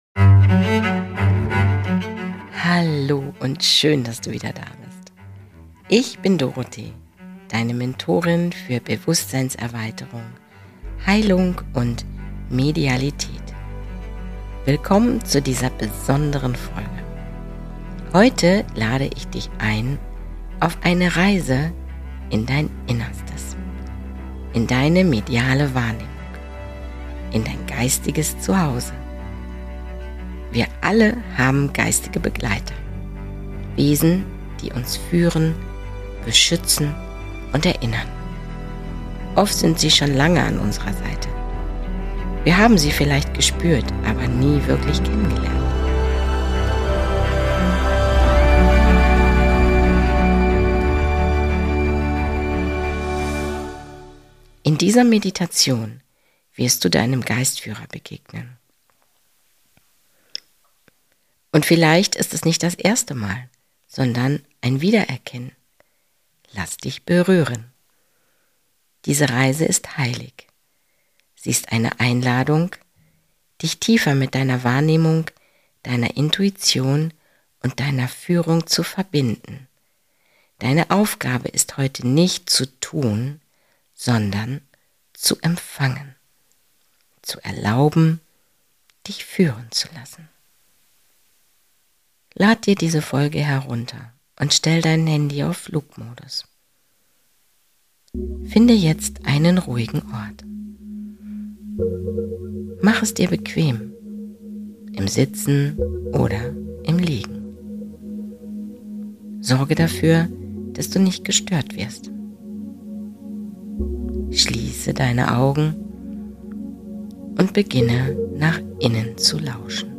Diese Meditation ist eine Einladung, dein spirituelles Potenzial zu erinnern, deine mediale Wahrnehmung zu öffnen und deinem Geistführer zu begegnen.